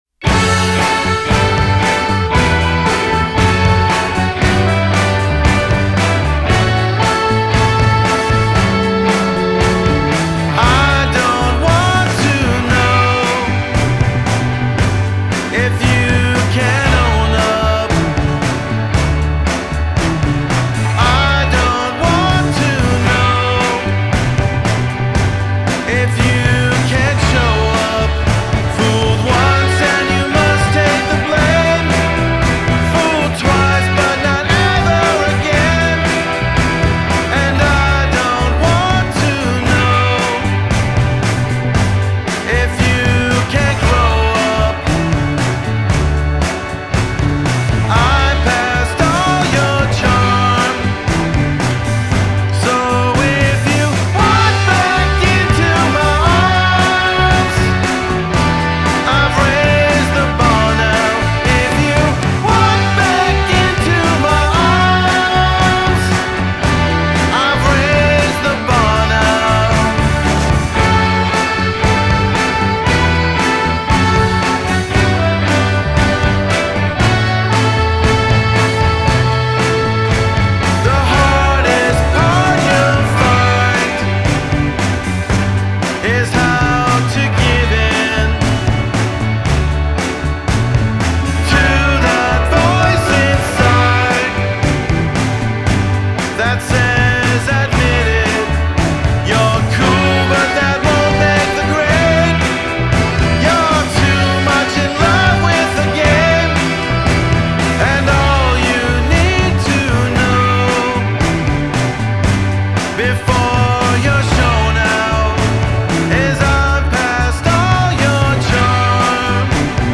updating a bygone sound, with horns